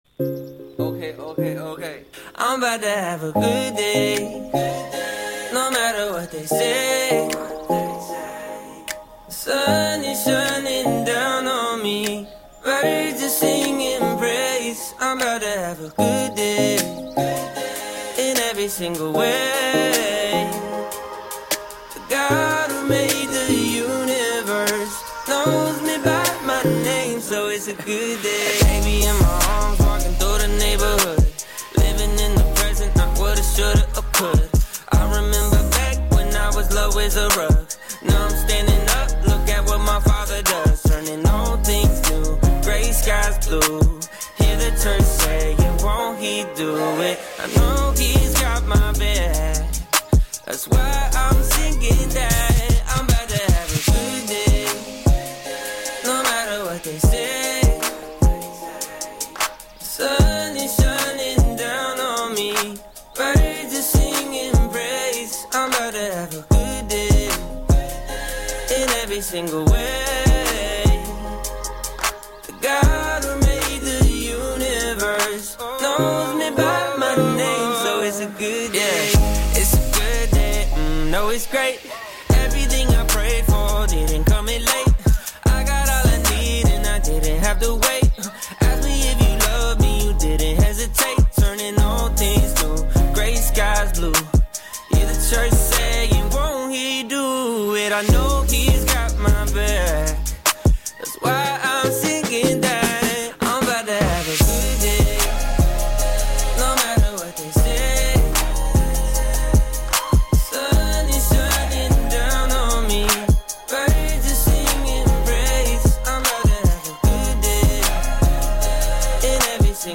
FaithMatters music to uplift inspire or just bring a point of difference